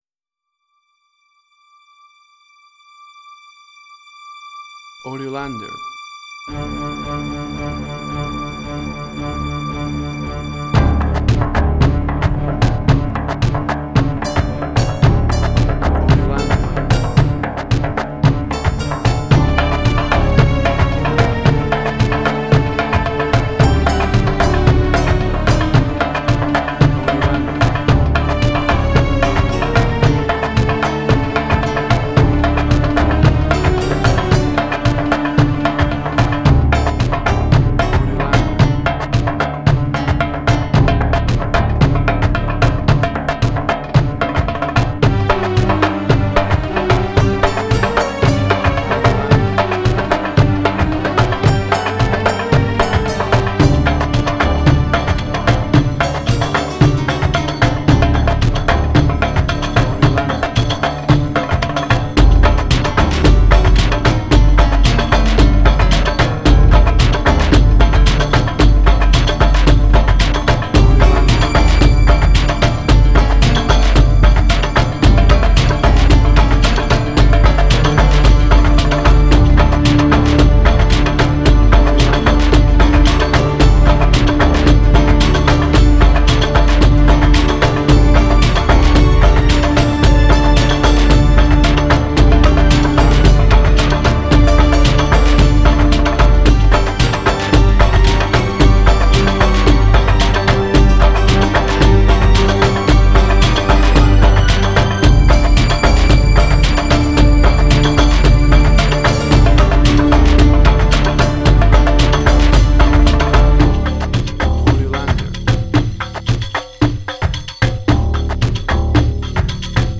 Tempo (BPM): 112